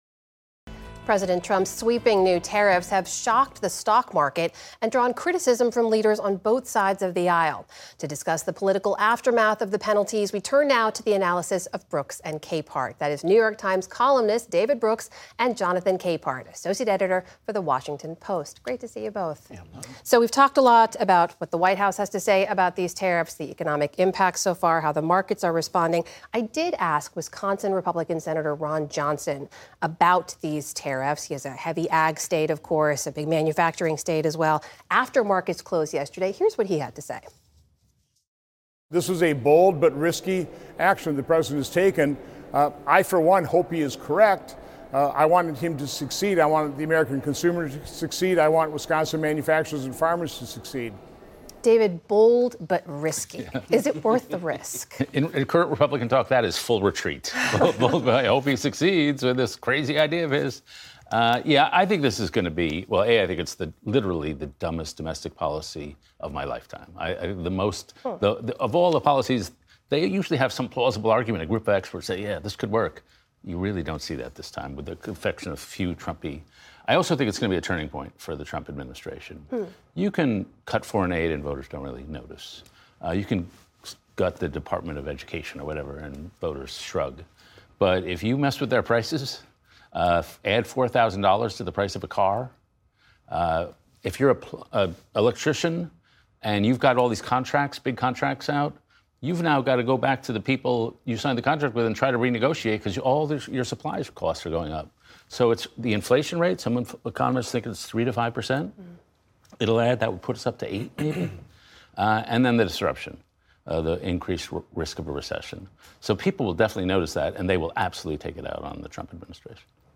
New York Times columnist David Brooks and Washington Post associate editor Jonathan Capehart join Amna Nawaz to discuss the week in politics, including President Trump’s sweeping new tariffs draw criticism from leaders on both sides of the aisle, how voters will respond to the move and Sen. Cory Booker's record-breaking speech on the Senate floor.